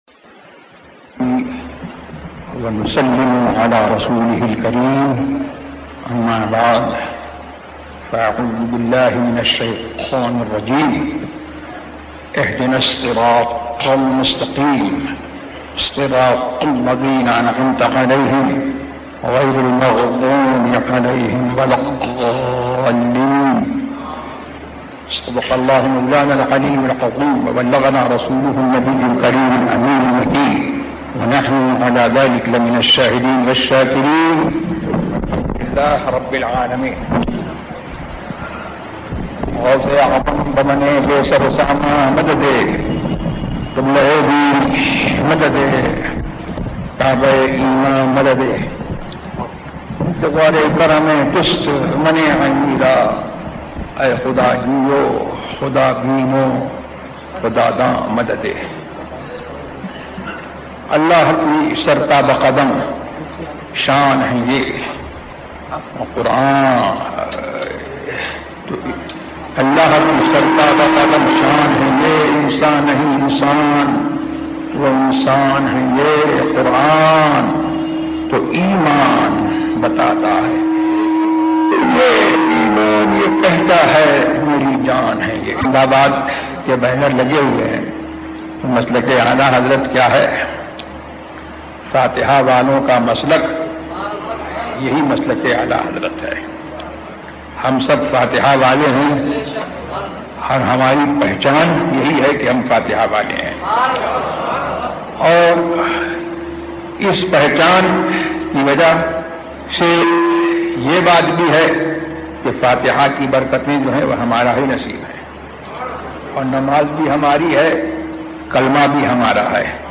سورۃ فاتحہ کا بیان ZiaeTaiba Audio میڈیا کی معلومات نام سورۃ فاتحہ کا بیان موضوع تقاریر آواز تاج الشریعہ مفتی اختر رضا خان ازہری زبان اُردو کل نتائج 956 قسم آڈیو ڈاؤن لوڈ MP 3 ڈاؤن لوڈ MP 4 متعلقہ تجویزوآراء
surah-fatiha-ka-bayan.mp3